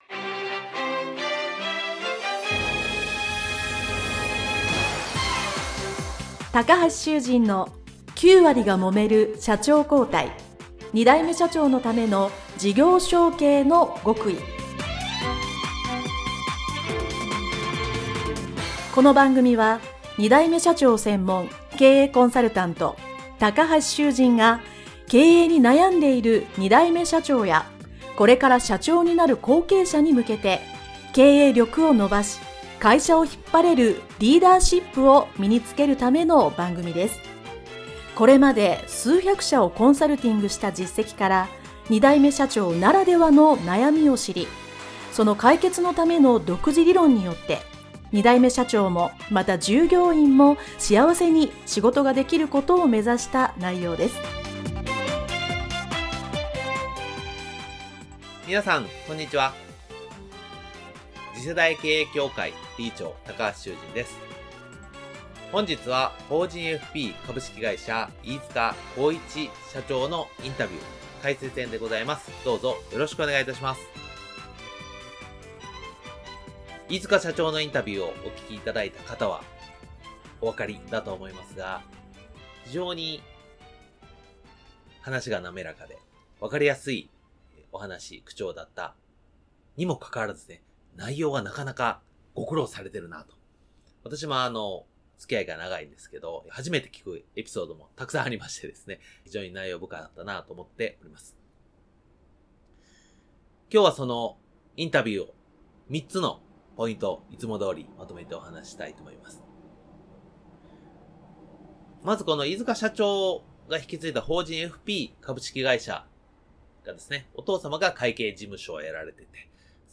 【インタビュー解説編】 - 9割がもめる社長交代 ～二代目社長が成功する極意～